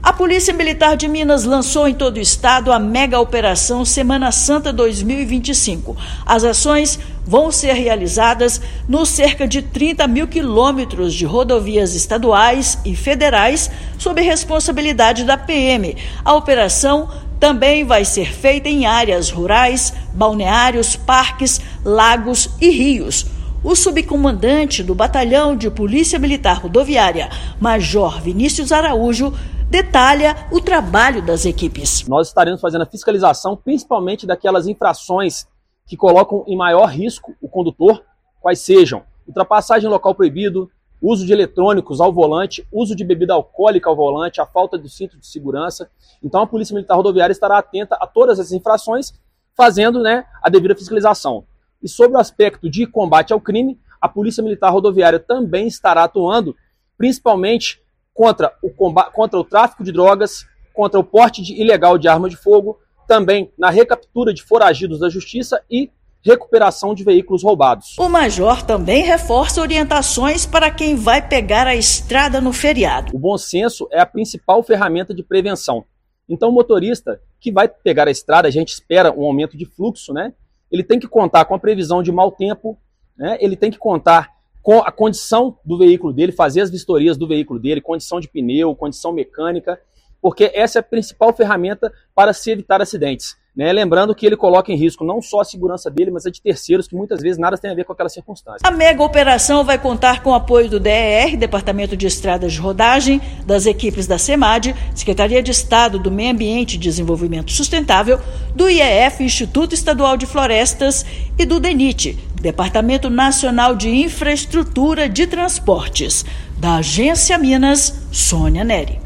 Ações abrangerão os cerca de 30 mil quilômetros de rodovias estaduais e federais delegadas sob a responsabilidade da PMMG, além de áreas rurais, balneários, parques, lagos e rios. Ouça matéria de rádio.